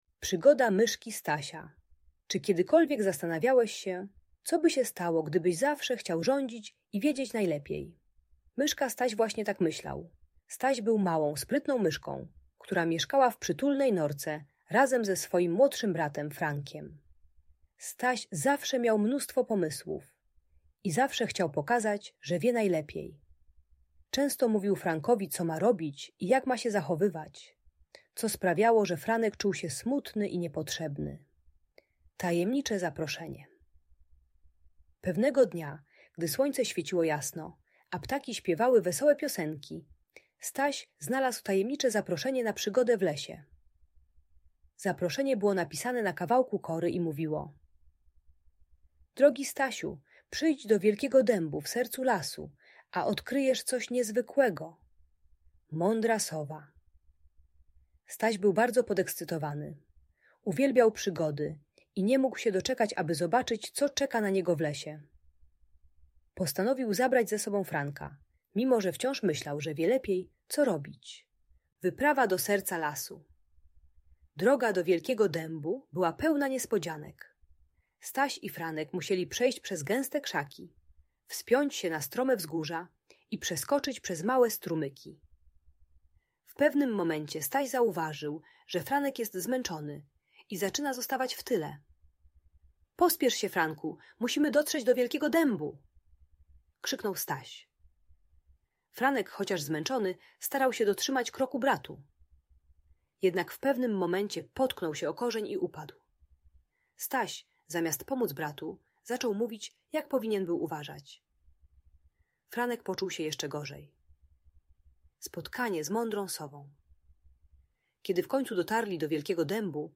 Przygoda Myszki Stasia - Bajka o współpracy i słuchaniu - Audiobajka dla dzieci